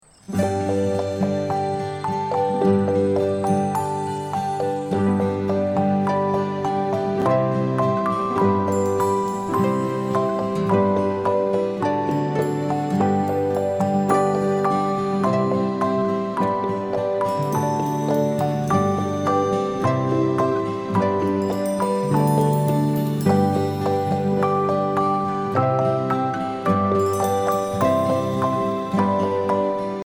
Listen to a sample of the instrumenal track.
Downloadable Instrumental Track